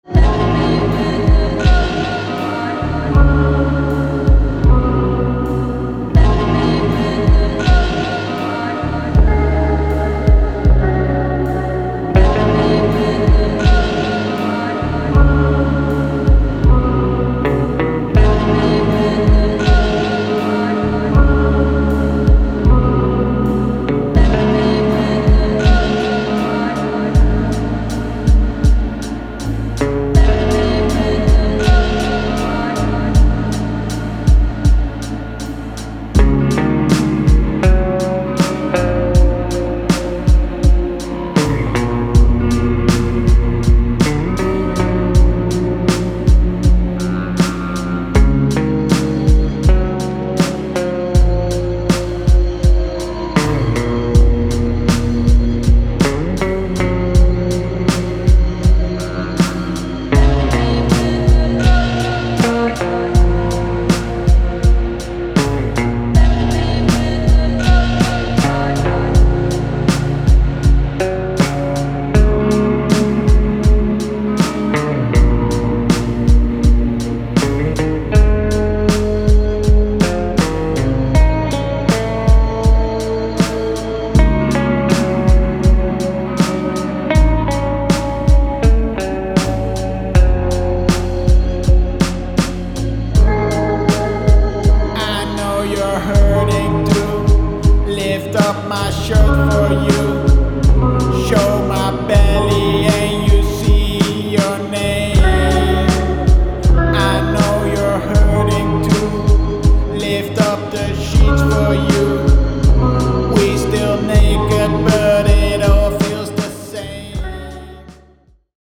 Tripped out Postpunk